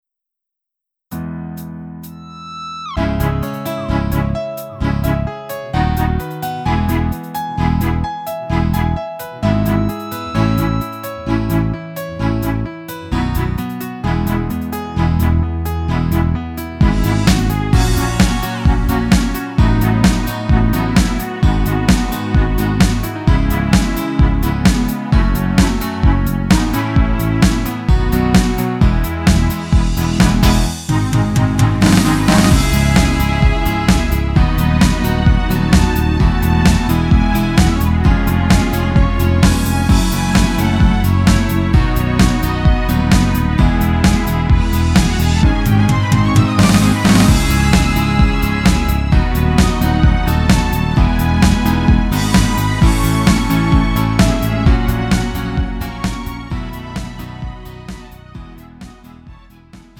음정 -1키 3:26
장르 가요 구분 Lite MR
Lite MR은 저렴한 가격에 간단한 연습이나 취미용으로 활용할 수 있는 가벼운 반주입니다.